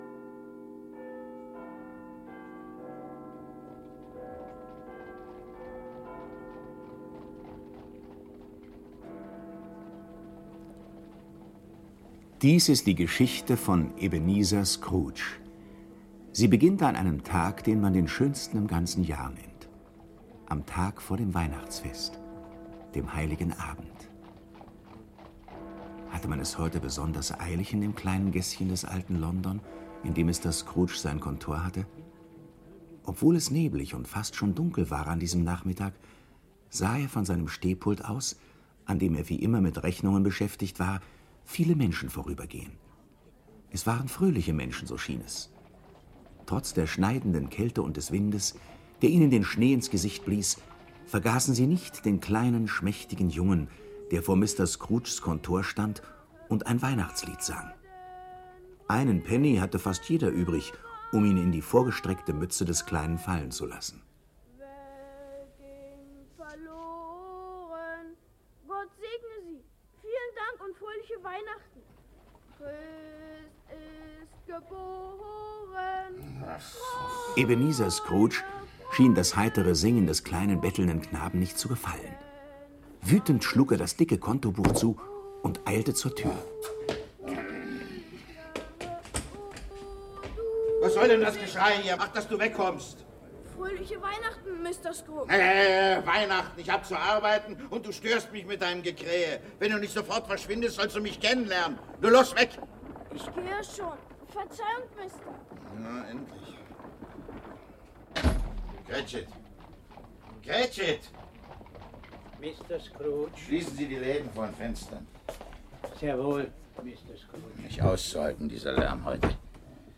Neuausgabe. Hörspiel mit Leonard Steckel u.v.a. (1 CD)